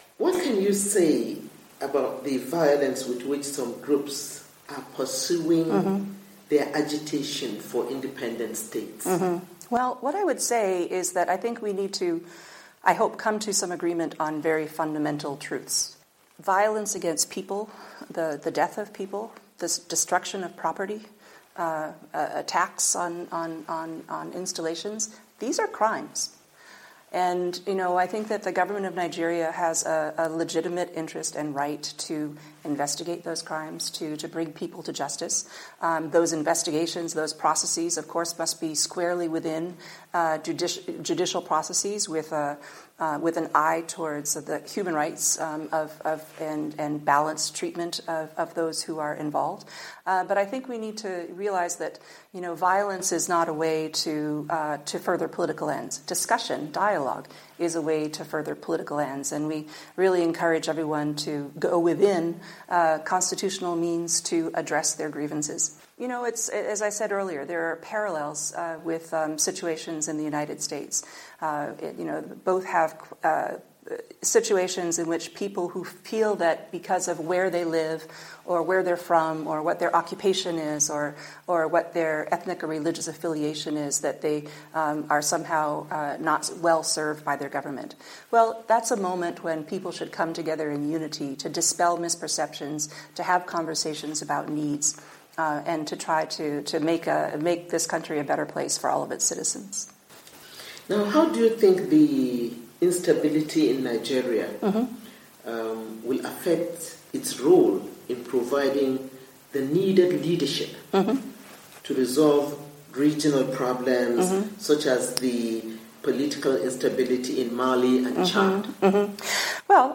In strongly worded interview, Mary Beth Leonard, the U.S. Ambassador to Nigeria, said separatist and sectarian violence in Nigeria must be curbed.